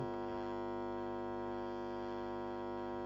Röhrenradio Minola Minerva: 50 Hz rauschen
brummen.mp3